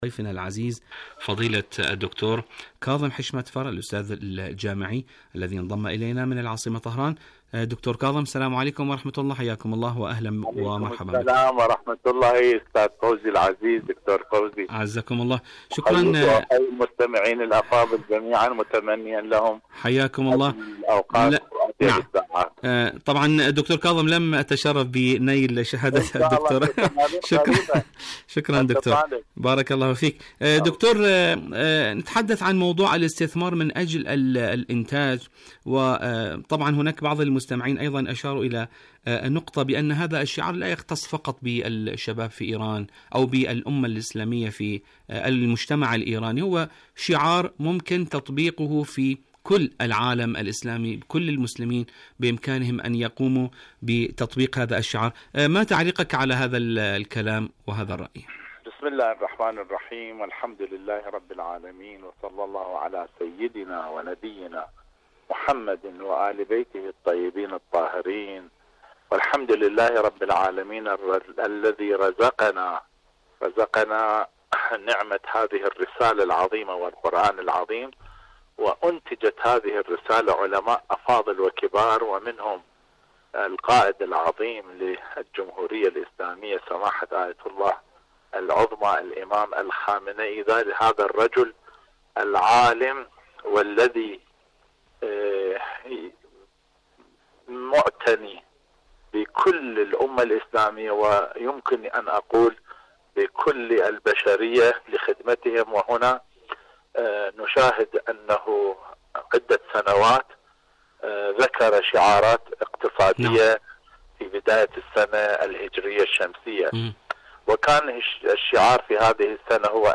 مقابلات برامج إذاعة طهران العربية برنامج دنيا الشباب الشباب مقابلات إذاعية الشباب والاستثمار من أجل الإنتاج الاستثمارات المتعلقة بشباب لماذا الاستثمار في الشباب؟